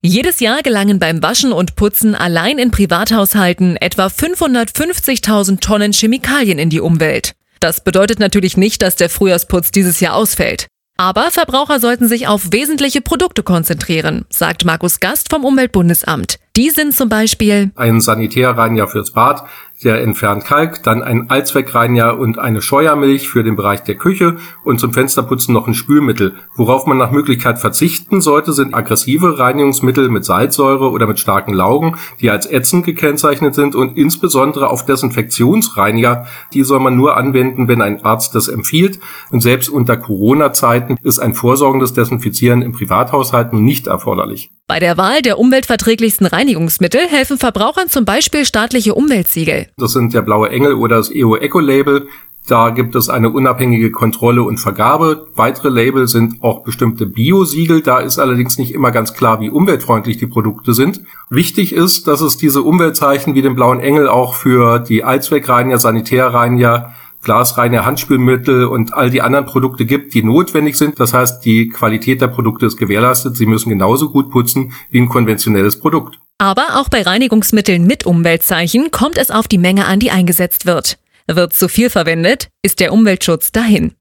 Beitrag (1:24 Minuten)
Beitrag: Weniger ist mehr – auch beim Frühjahrsputz!